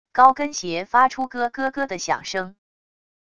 高跟鞋发出咯咯咯的响声wav音频